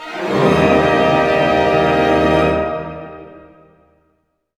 Index of /90_sSampleCDs/Roland - String Master Series/ORC_Orch Gliss/ORC_Major Gliss